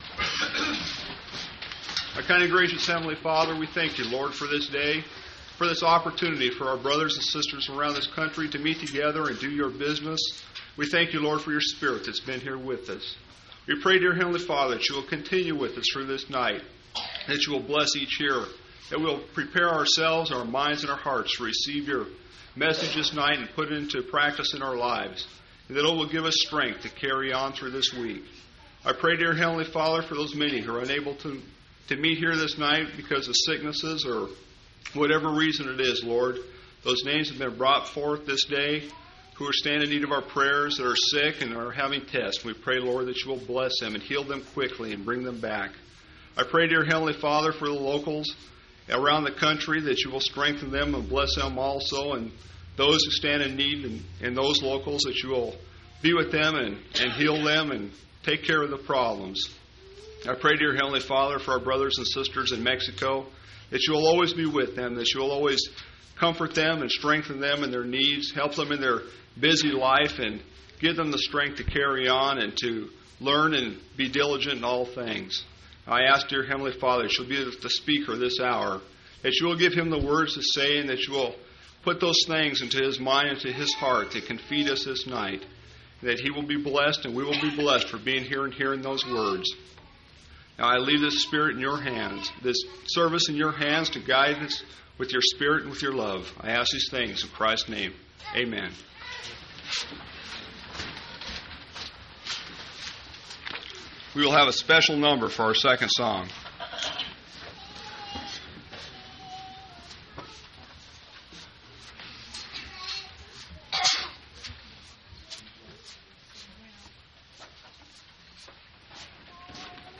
4/2/1990 Location: Temple Lot Local (Conference) Event: General Church Conference